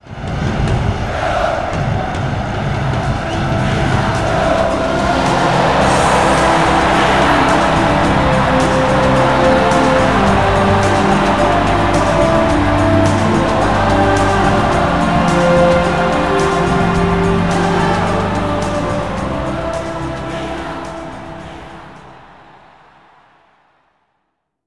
chants.big